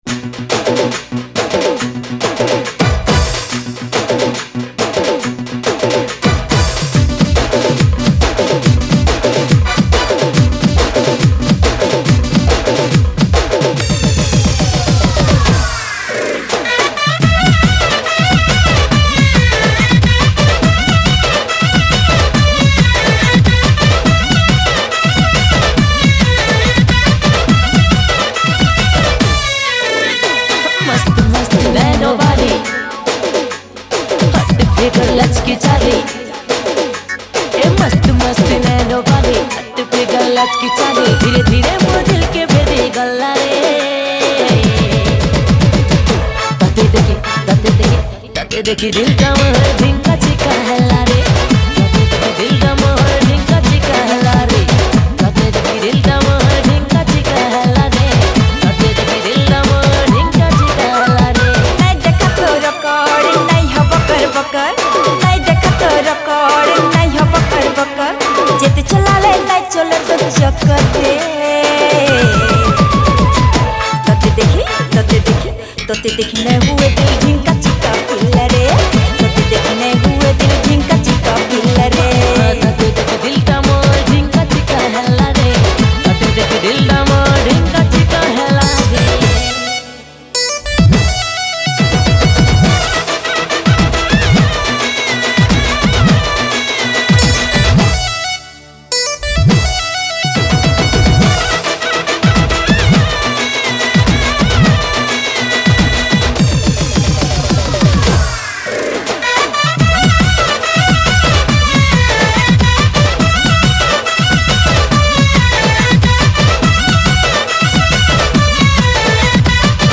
Category: New Sambalpuri Songs 2022